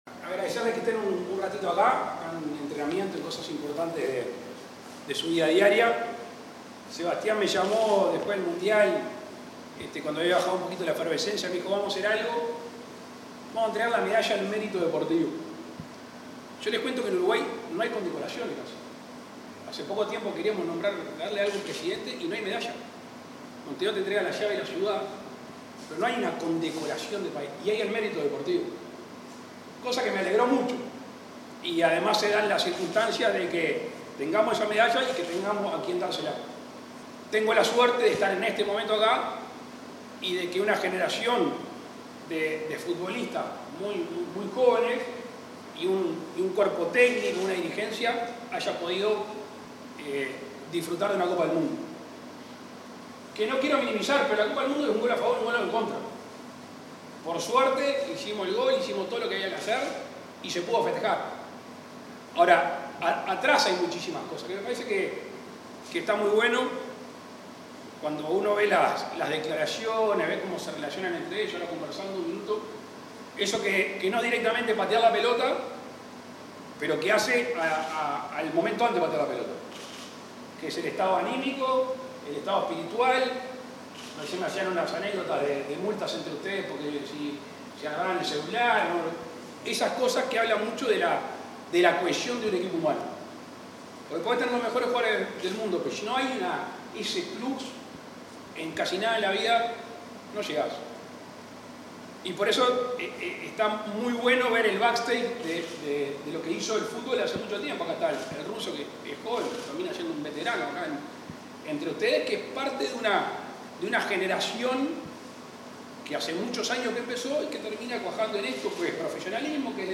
Palabras del presidente Luis Lacalle Pou
Este miércoles 2, el presidente de la República, Luis Lacalle Pou, reconoció, en la residencia de Suárez, a la delegación de fútbol sub-20 campeona